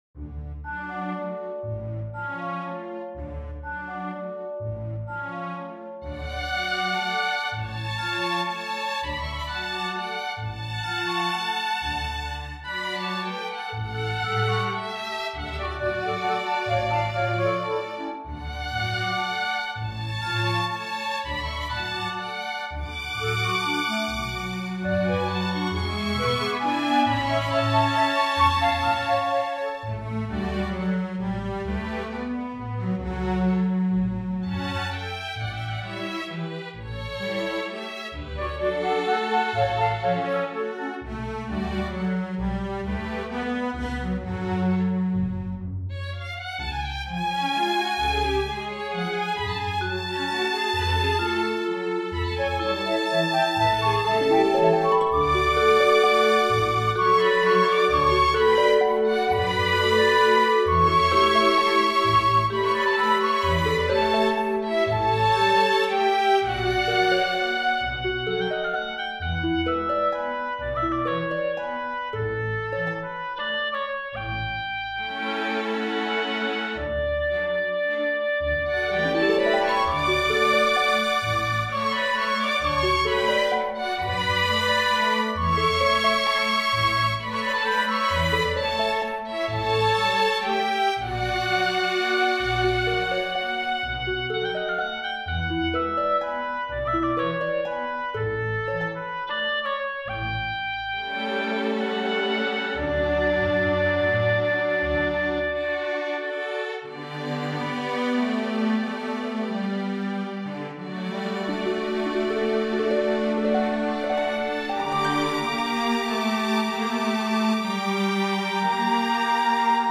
Fl Ob Cl Hp Cel Hp Cel SoloVn VnⅠ VnⅡ Va Vc Cb
音源はＶＳＬを使って演奏しています。